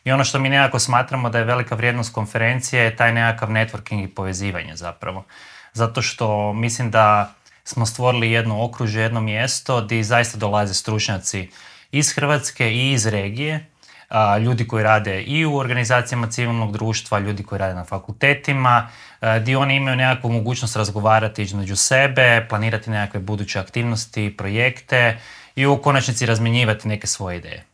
ZAGREB - Uoči četvrtog izdanja konferencije Autizam i mentalno zdravlje, u Intervjuu Media Servisa razgovarali smo